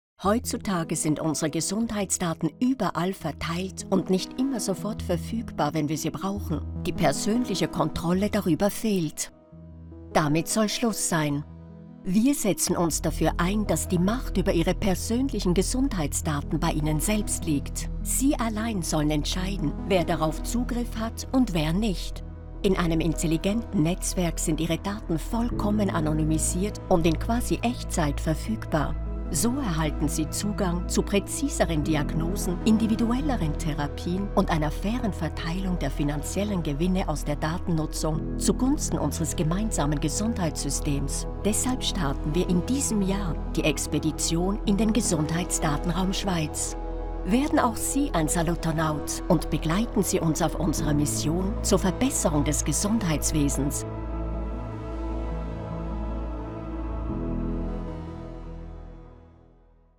Allemand (Suisse)
Naturelle, Mature, Amicale, Polyvalente, Chaude
Corporate